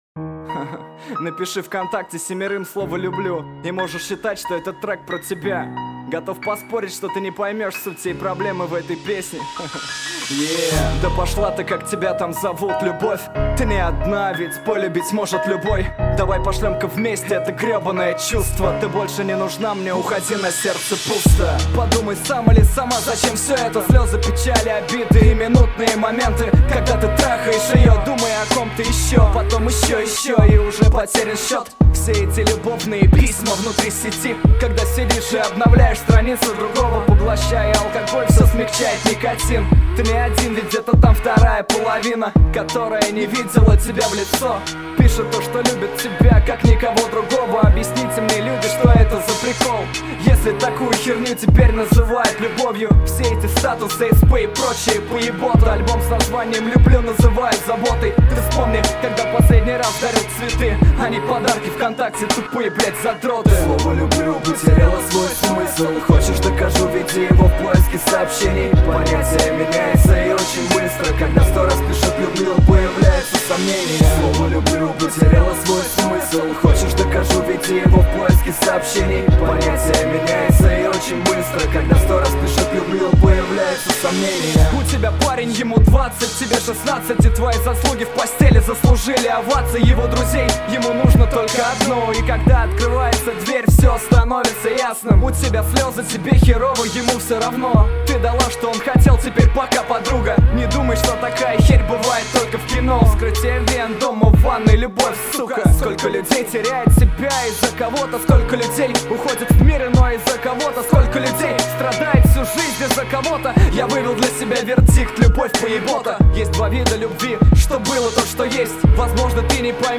Главная » Музыка » Русский рэп